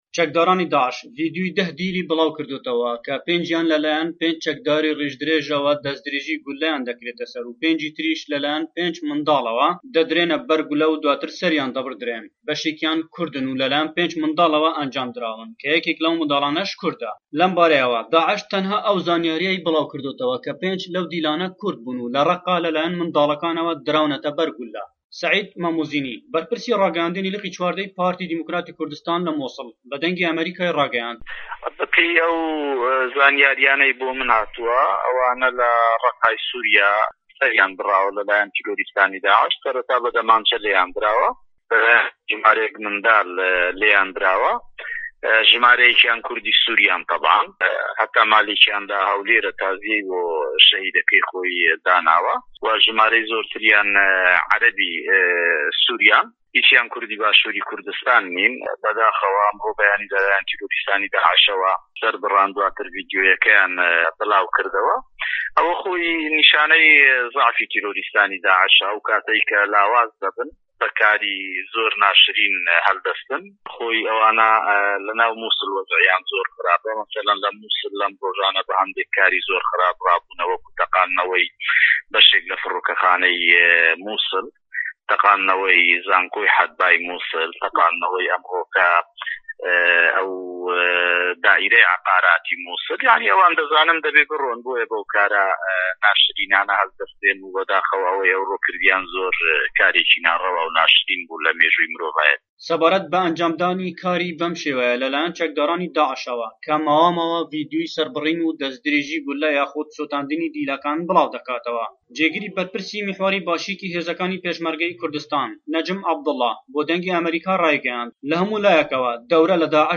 دقی ڕاپۆرتی